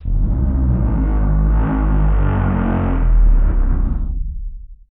MOAN EL 09.wav